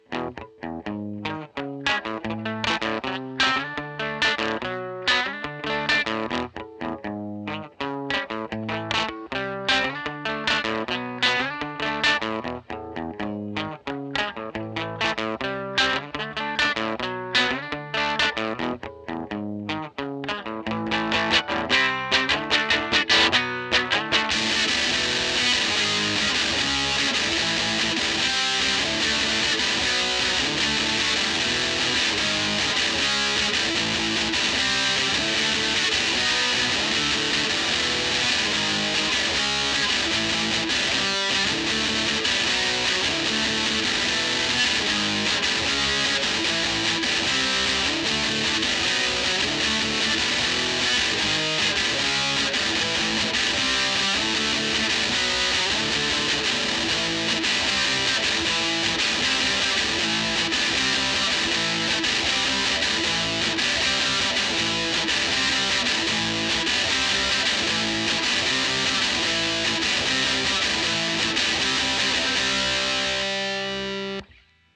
They're the same (almost) - still crazily thin and weedy.